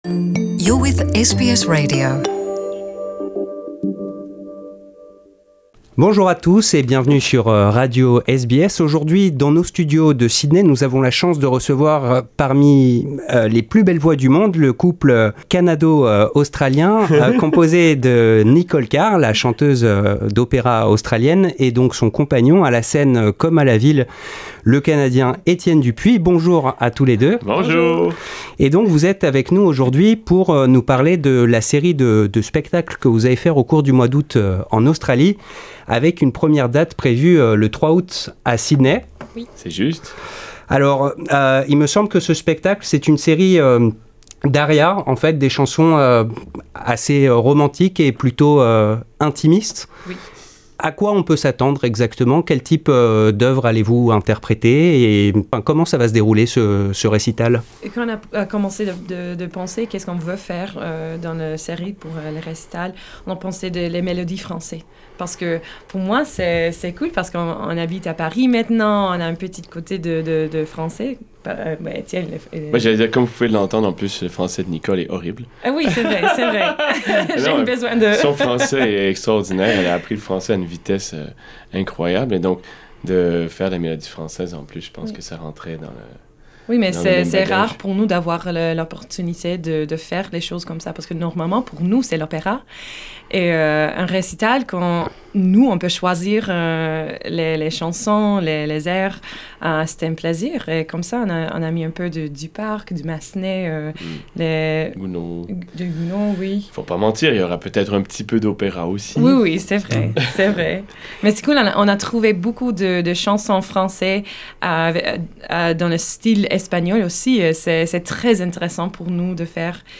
Les chanteurs lyriques Etienne Dupuis et Nicole Car donneront trois récitals en Australie au cours du mois prochain. Couple sur scène, ils se livrent lors de leur passage dans nos studios.